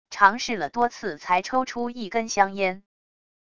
尝试了多次才抽出一根香烟wav音频